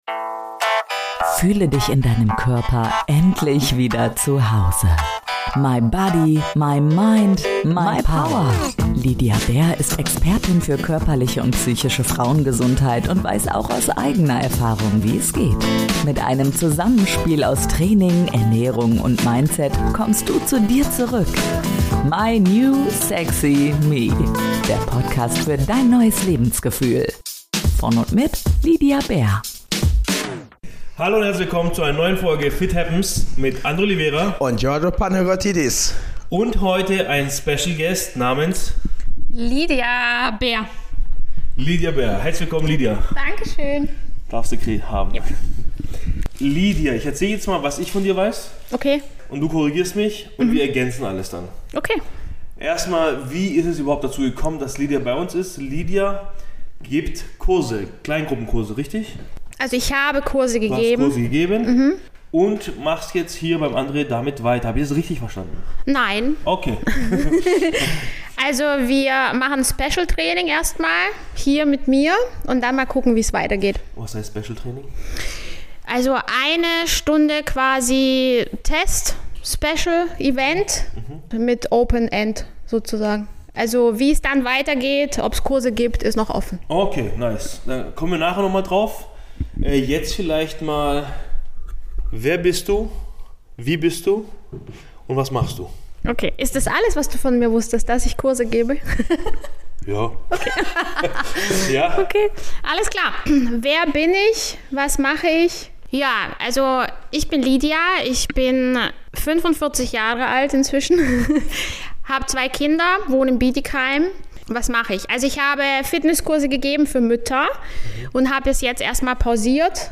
Ich formuliere klare Forderungen an Männer und stoße damit natürlich auf spürbare, teilweise hitzige Gegenwehr im Studio.
Eine Folge voller Energie, Reibung und ehrlicher Meinungen.